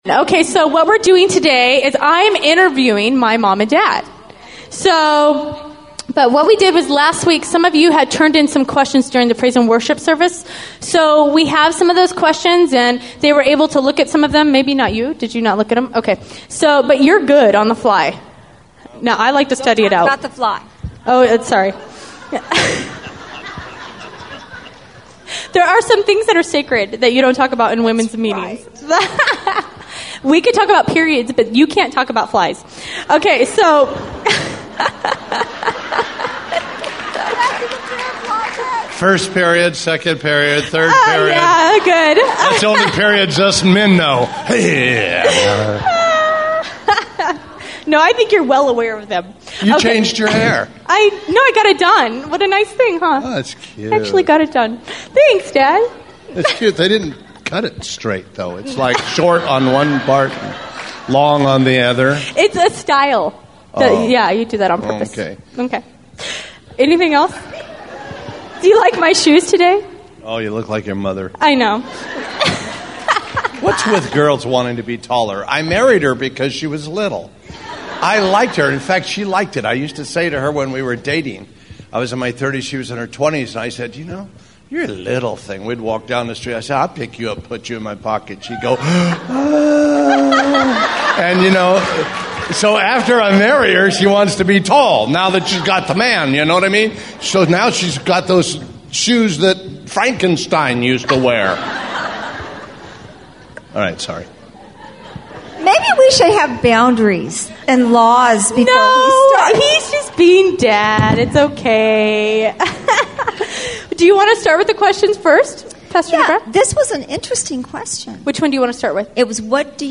Welcome to The Rock Church's Archives.